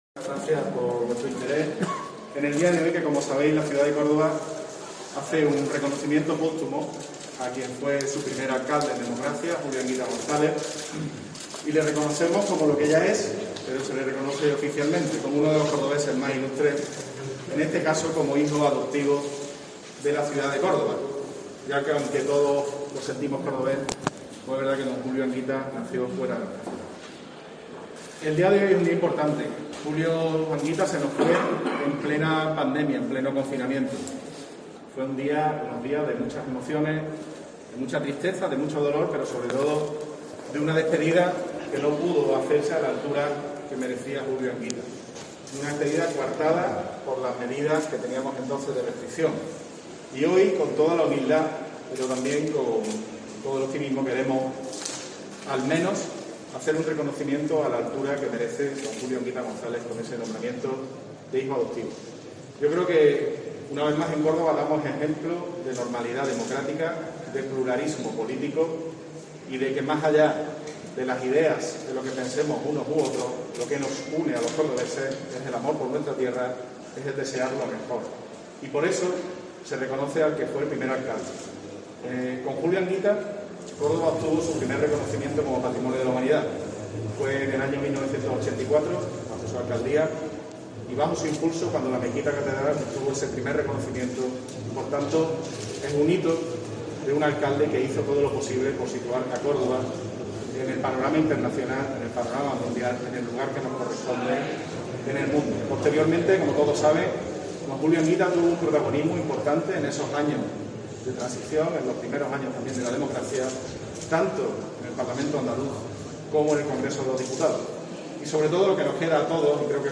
Representantes institucionales, políticos de todos los partidos y miembros de colectivos sociales arroparon a la familia durante la ceremonia